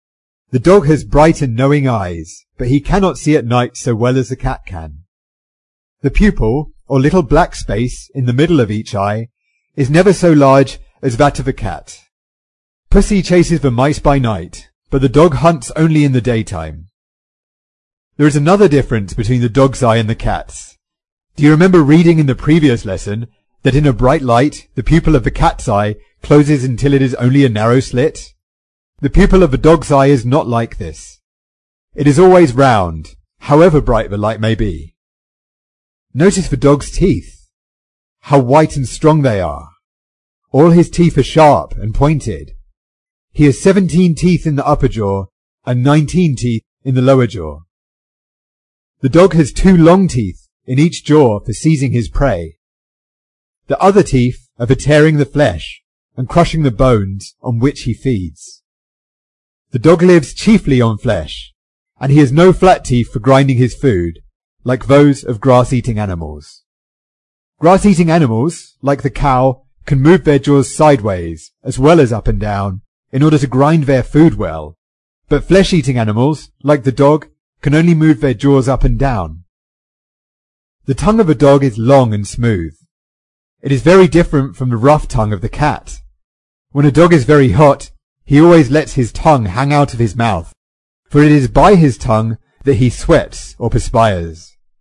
在线英语听力室英国学生科学读本 第47期: 人类的朋友(2)的听力文件下载,《英国学生科学读本》讲述大自然中的动物、植物等广博的科学知识，犹如一部万物简史。在线英语听力室提供配套英文朗读与双语字幕，帮助读者全面提升英语阅读水平。